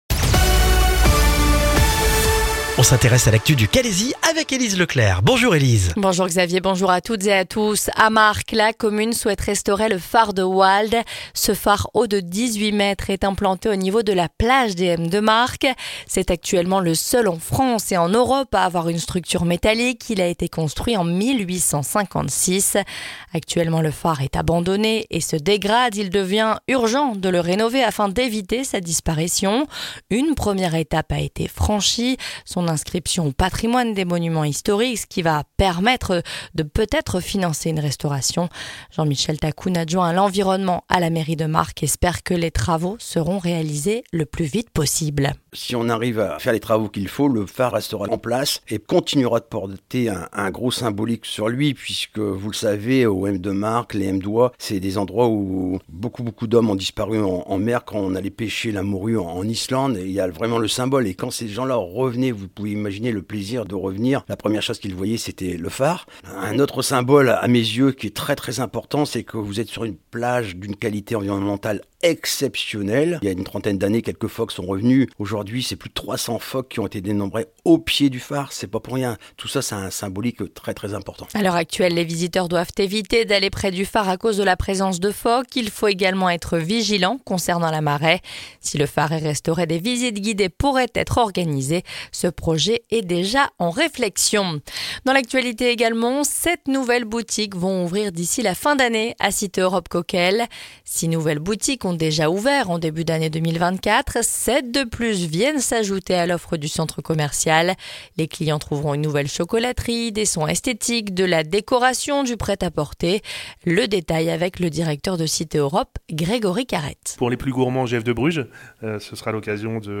Le journal du jeudi 3 octobre dans le Calaisis